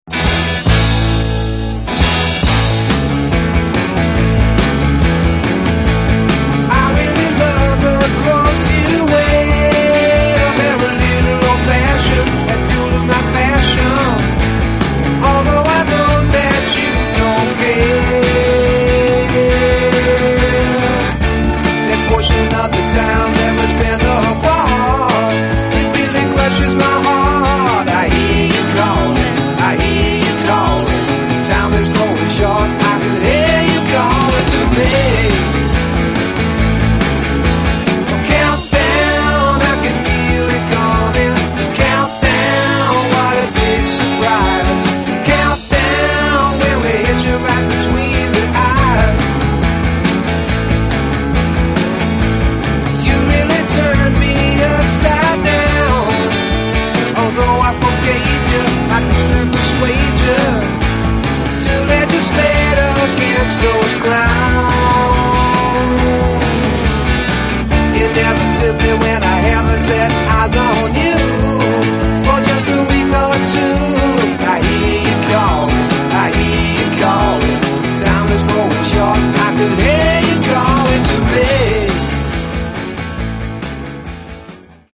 hard rock band